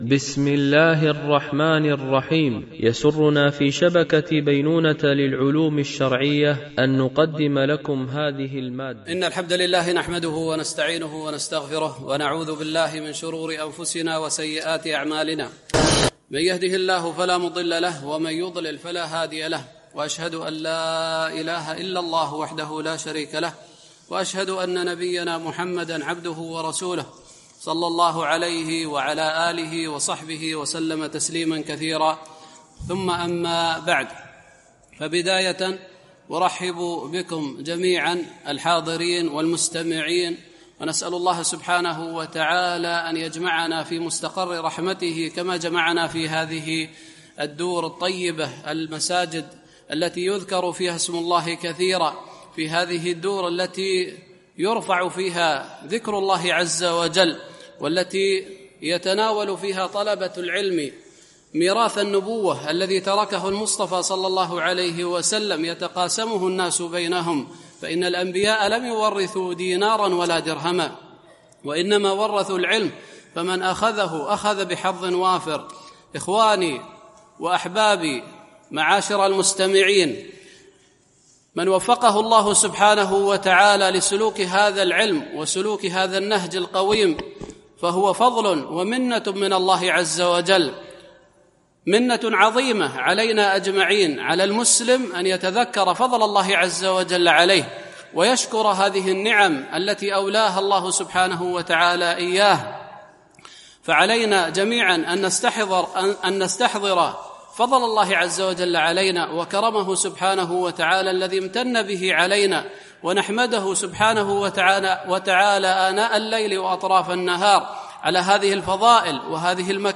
شرح كلمة الإخلاص وتحقيق معناها ـ الدرس 1
دورة علمية شرعية: لمجموعة من المشايخ الفضلاء، بمسجد عائشة أم المؤمنين - دبي (القوز 4)